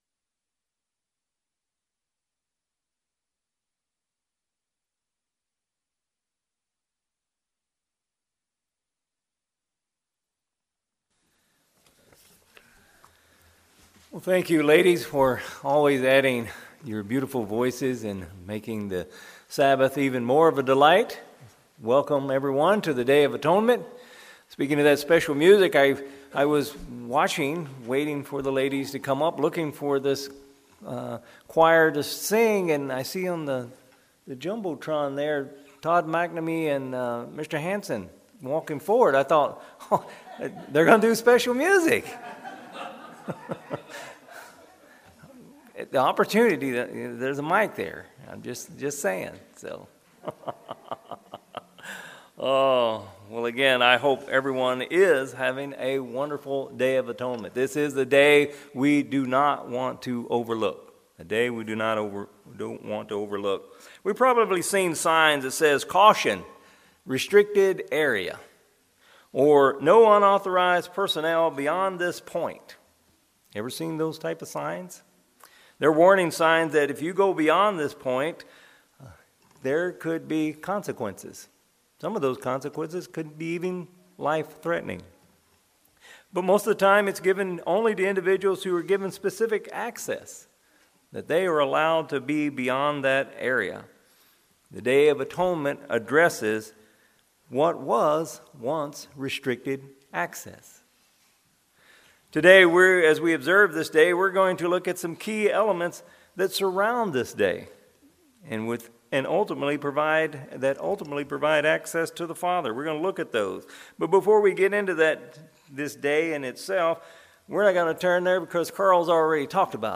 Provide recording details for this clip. Given in Salem, OR